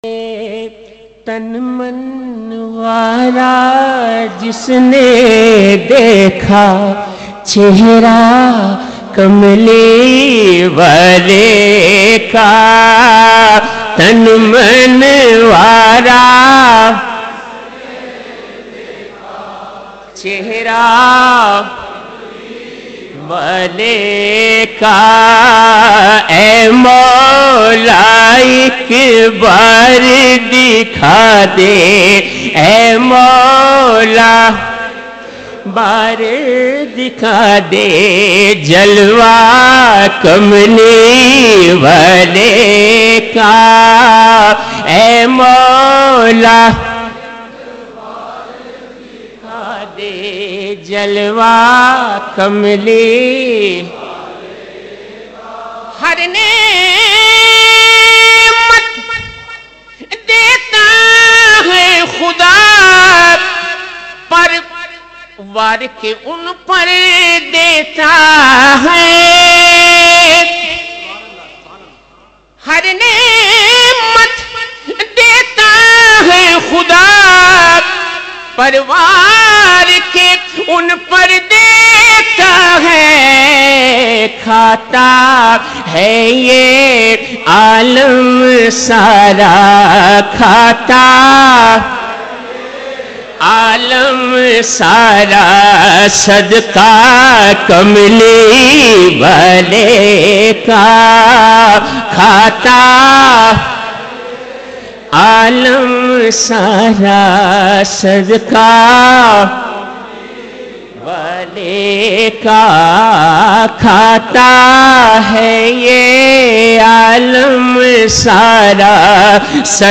Known for his melodious voice and emotive delivery